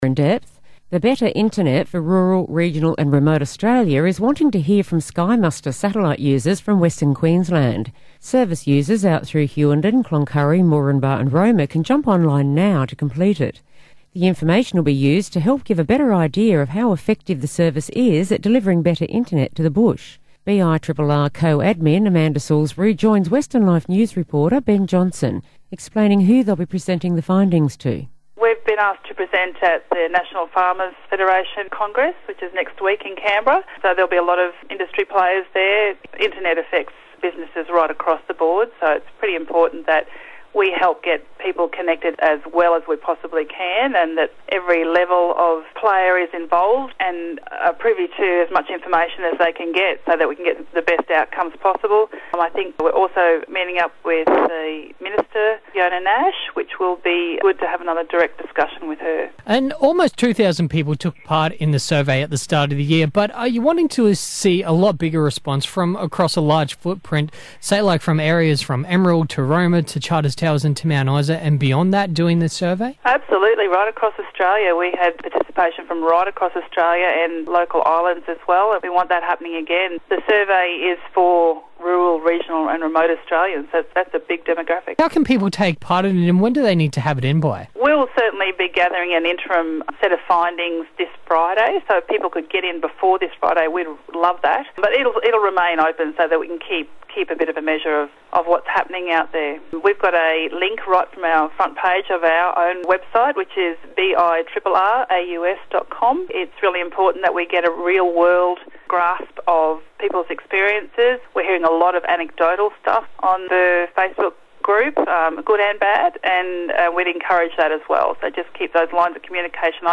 Interviews on Austereo (SkyMuster Survey). (Sept 2016)